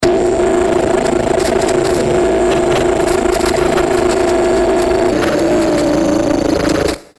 دانلود آهنگ رادیو 25 از افکت صوتی اشیاء
جلوه های صوتی